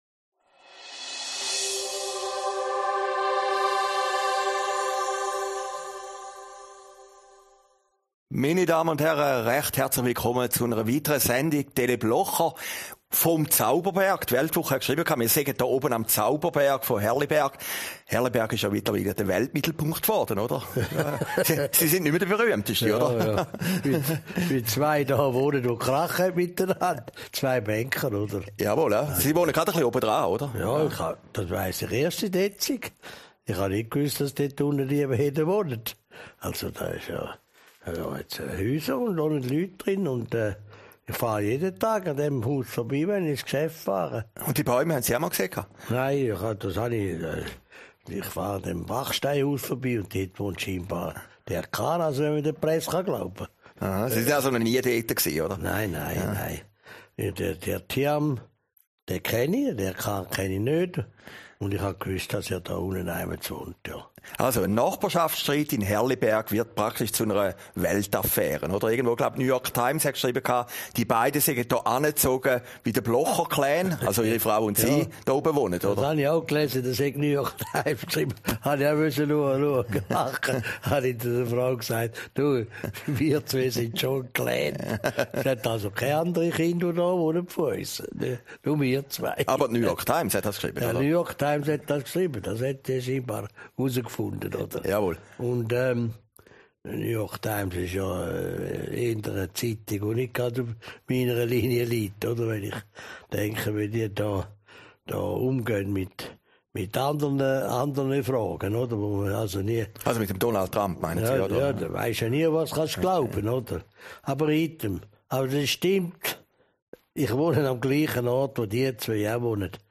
Aufgezeichnet in Herrliberg, 4. Oktober 2019